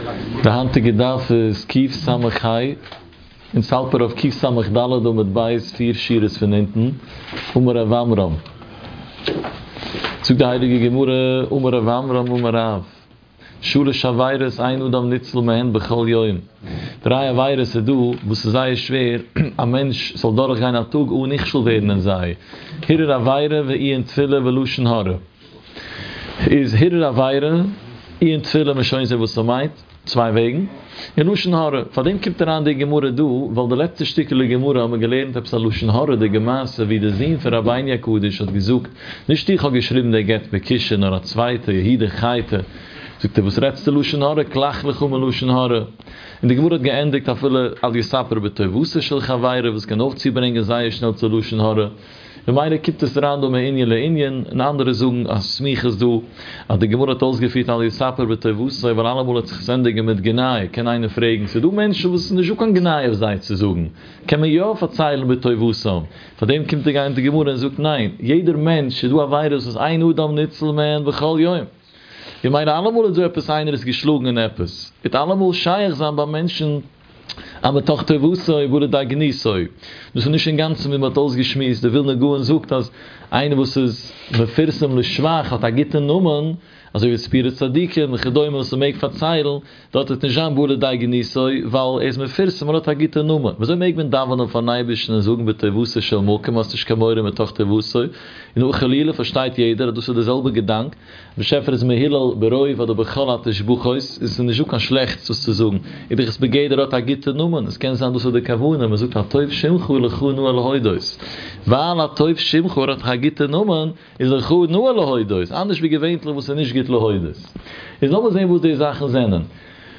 Daf Yomi